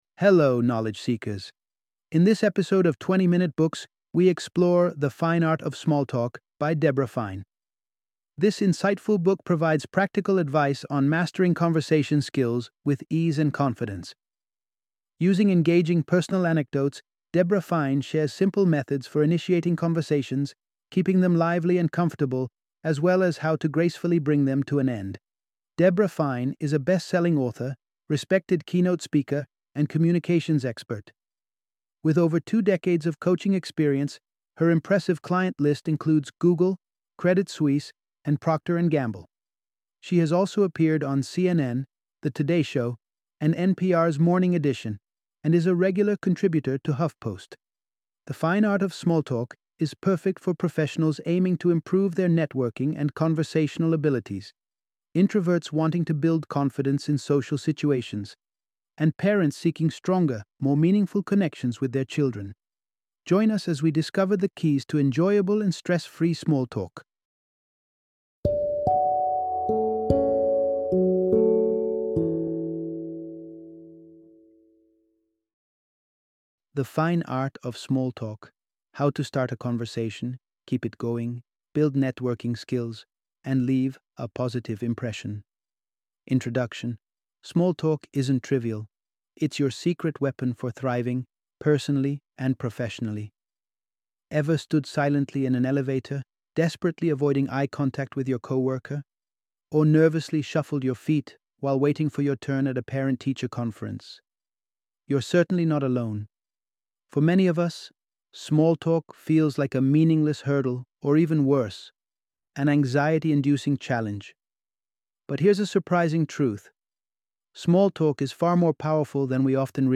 The Fine Art Of Small Talk - Audiobook Summary